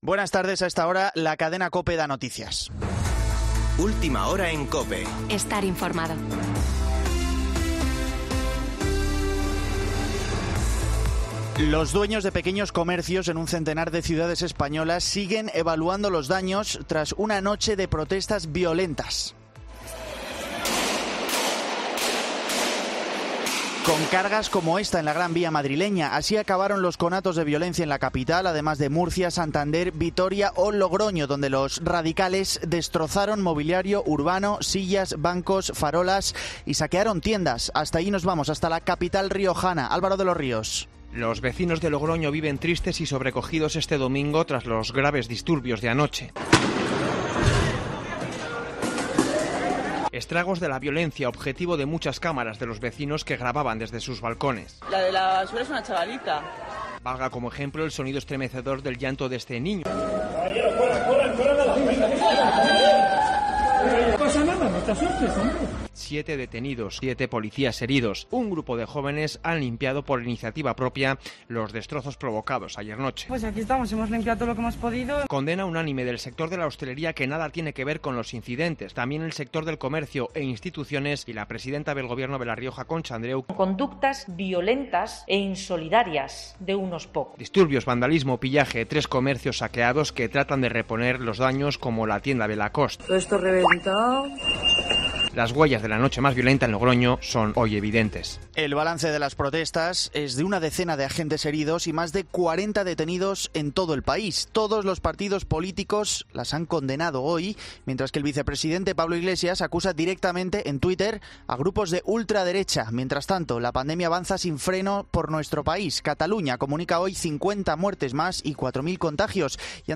Boletín de noticias de COPE del 1 de noviembre de 2020 a las 19.00 horas